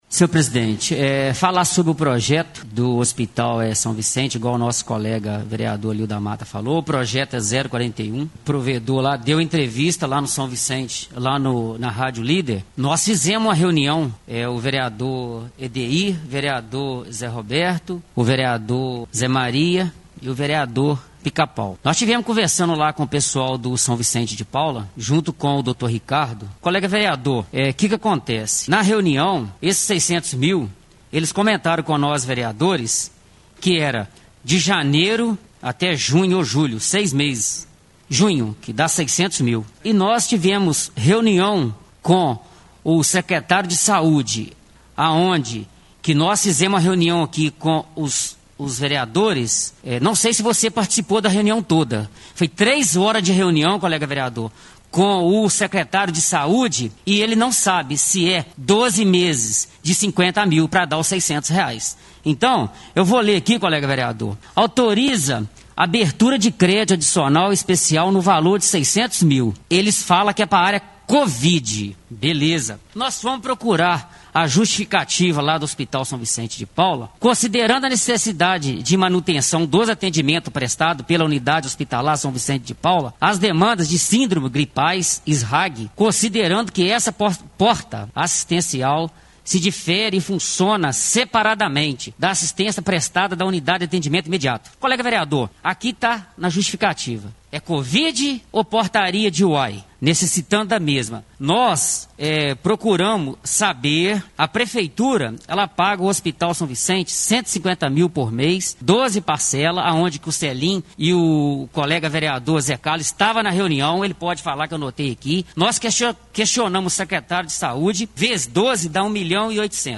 Vereador Gilson Pica Pau em sessão ordinária da Câmara Municipal de Ubá 26 de abril de 2021